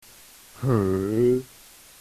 苏州话城区的七单字调
阳平 223 穷jion223  田die223  寒ghoe223
ghoe223.mp3